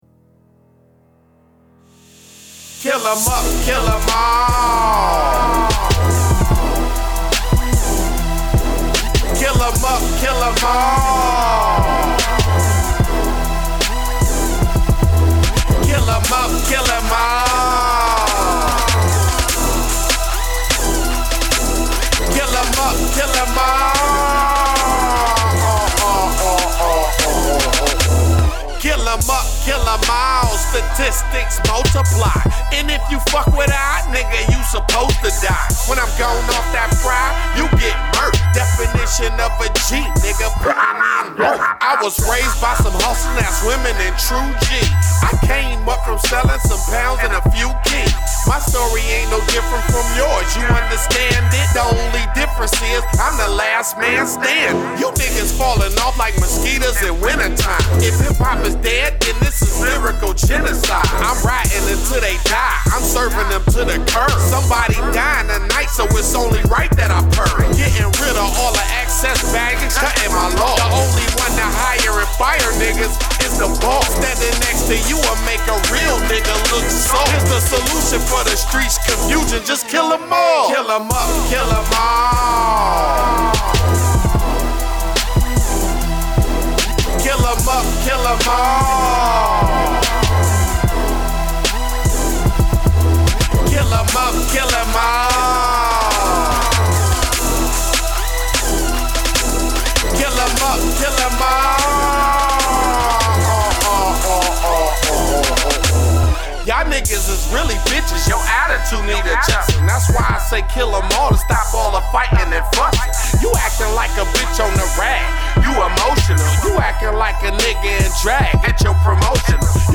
Trap
this time he comes a little darker and grittier and grimier